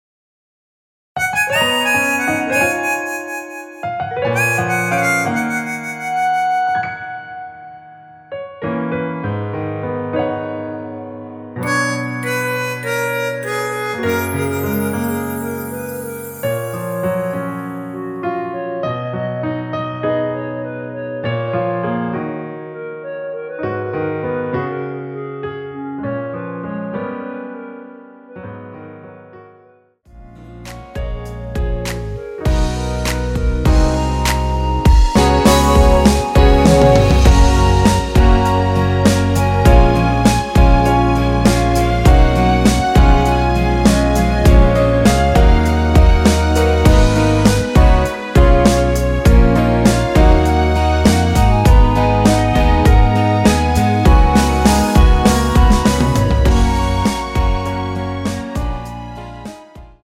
원키에서(-1)내린 멜로디 포함된 MR입니다.
F#
앞부분30초, 뒷부분30초씩 편집해서 올려 드리고 있습니다.
중간에 음이 끈어지고 다시 나오는 이유는